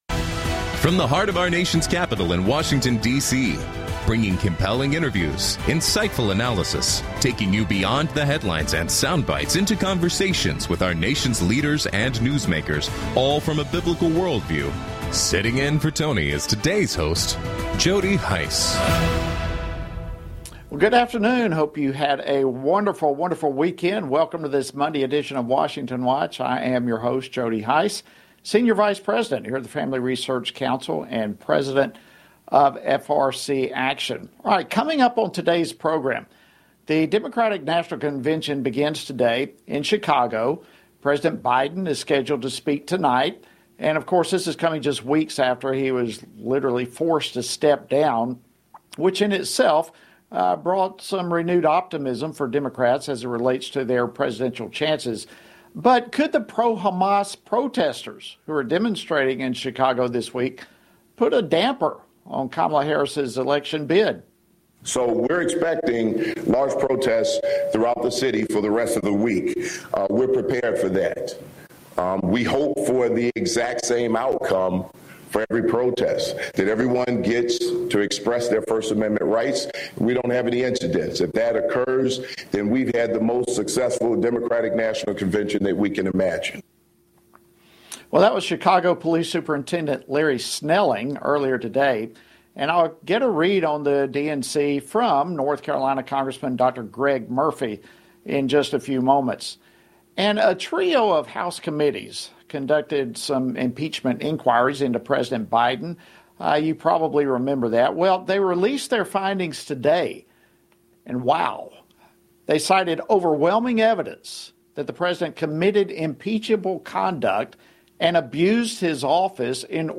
Hard hitting talk radio never has been and never will be supported by the main stream in America!